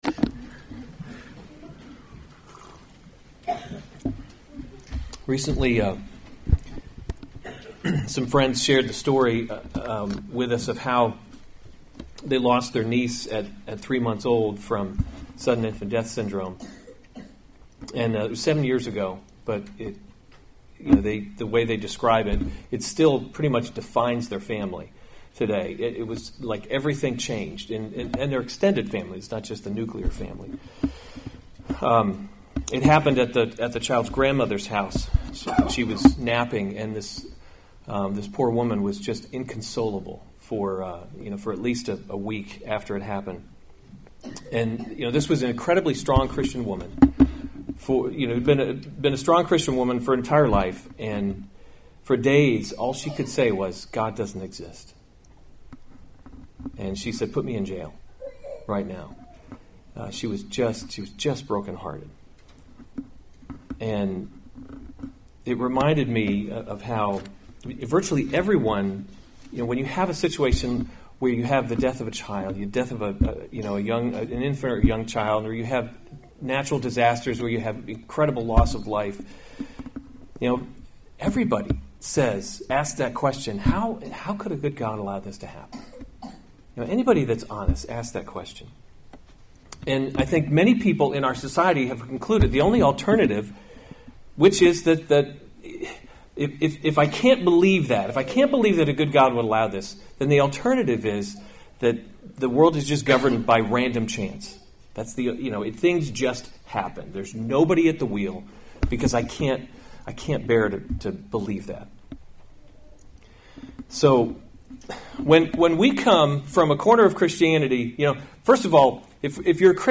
February 23, 2019 1 Kings – Leadership in a Broken World series Weekly Sunday Service Save/Download this sermon 1 Kings 12:1-24 Other sermons from 1 Kings Rehoboam’s Folly 12:1 Rehoboam went […]